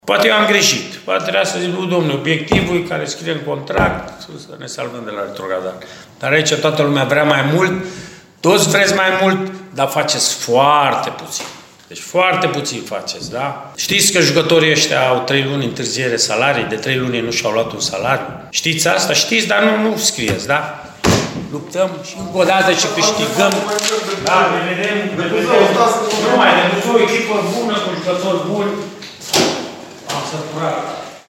Tehnicianul arădenilor a vorbit mai puțin despre meci la conferința de presă de astăzi.